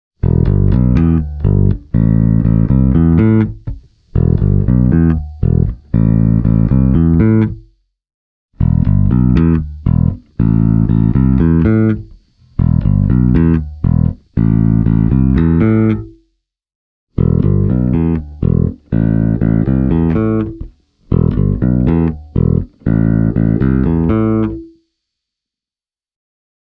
The Legend 4 Classic’s SSD-pickups have quite a high output, with the signal being rich in deliciously growling mids.
I recorded the audio clips via a Focusrite Saffirer 6 USB -soundcard straight into the audiosequencer, without any kind of amplifier modelling. Each clip starts with the neck pickup:
Spector Legend 4 Classic – fingerstyle